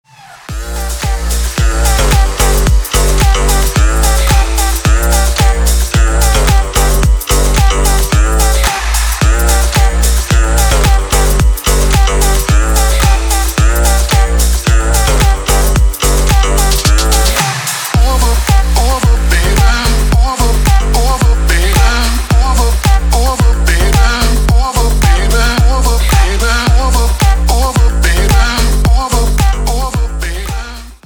• Качество: 320, Stereo
заводные
dance
Electronic